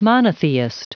Prononciation du mot monotheist en anglais (fichier audio)
Prononciation du mot : monotheist